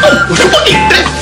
combobreaksoft.wav